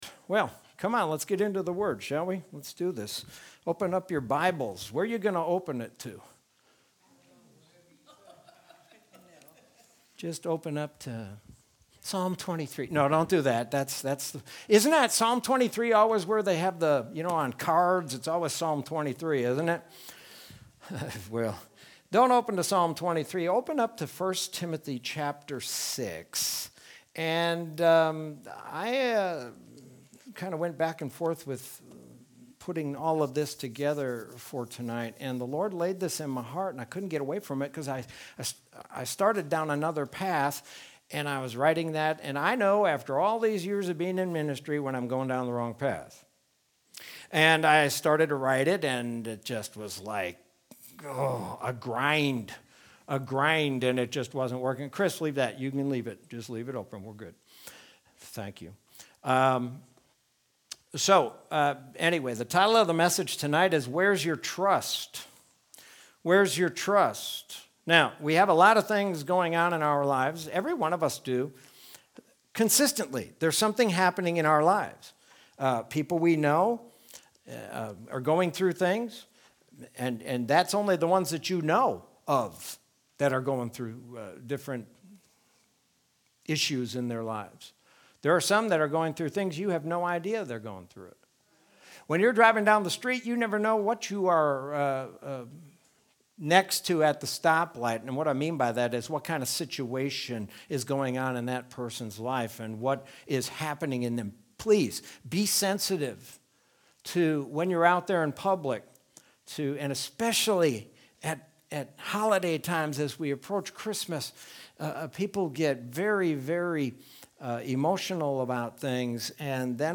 Sermon from Wednesday, December 9th, 2020.